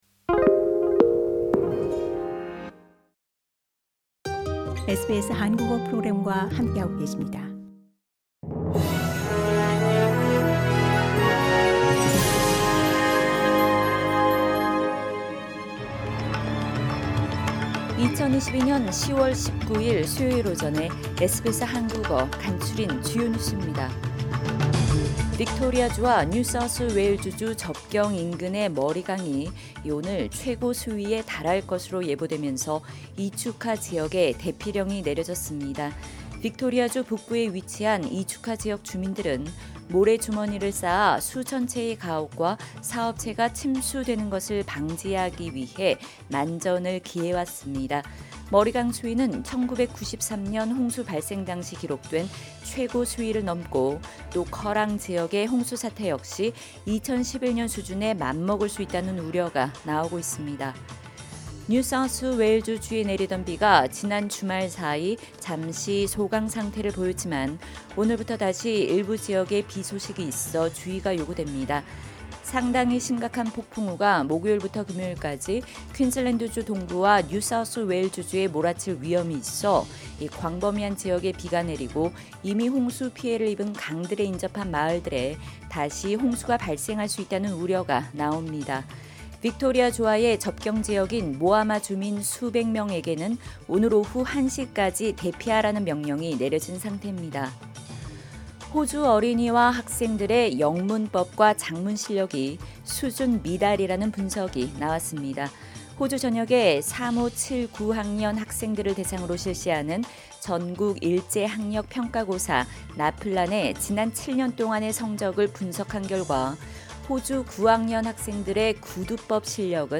2022년 10월 19일 수요일 아침 SBS 한국어 간추린 주요 뉴스입니다.